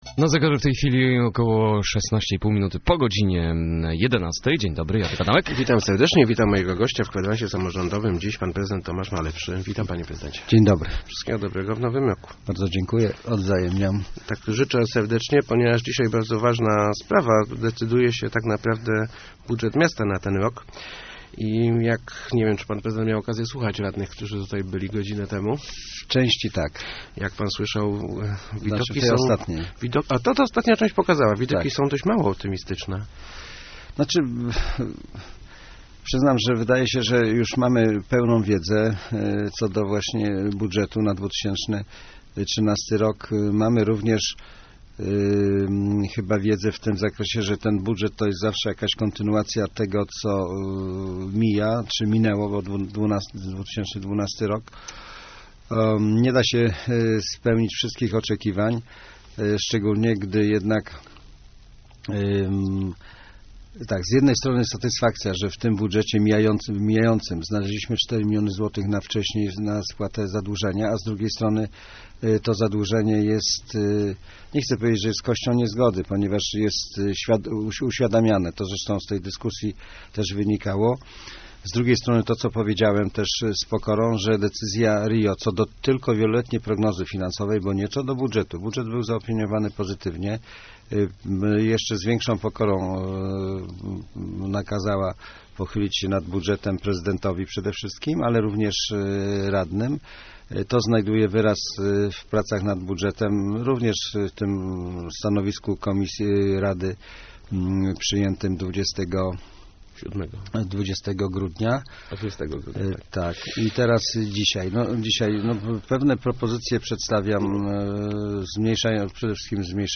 Gościem Kwadransa jest prezydent Leszna Tomasz Malepszy.